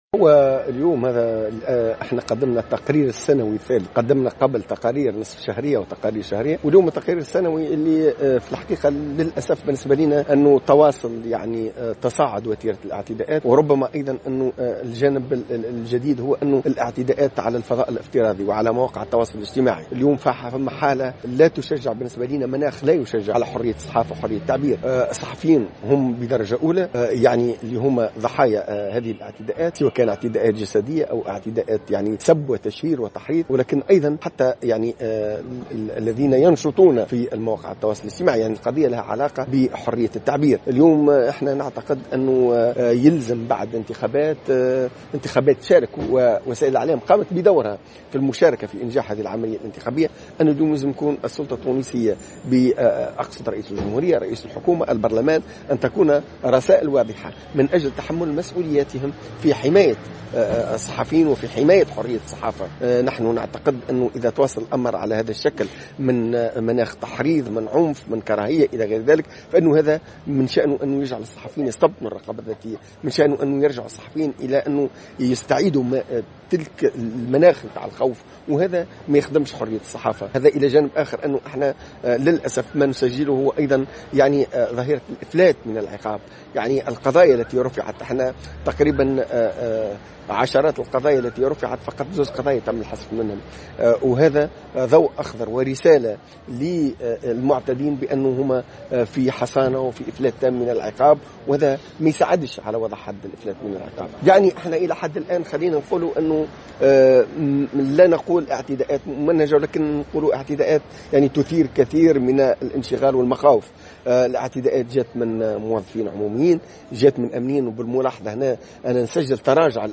في تصريح للجوهرة "أف أم"